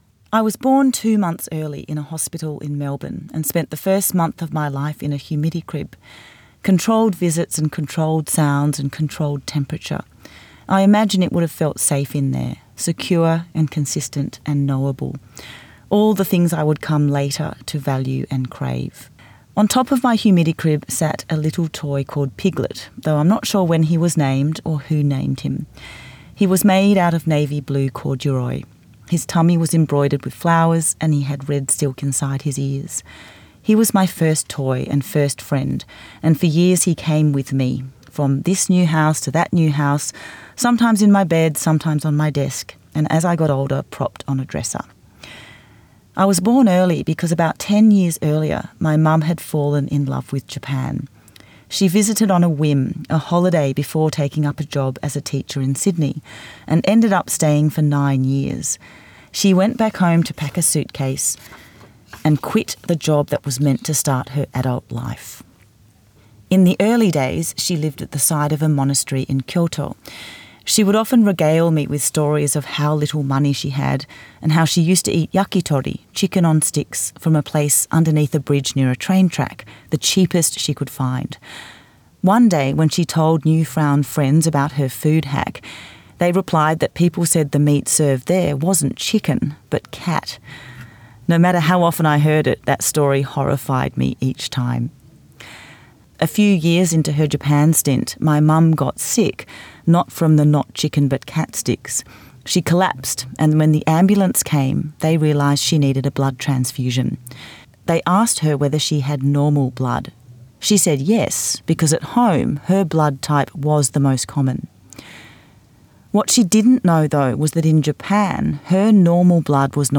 Recorded at Bellingen Readers and Writers Festival 2025
Kumi-Taguchi-read.mp3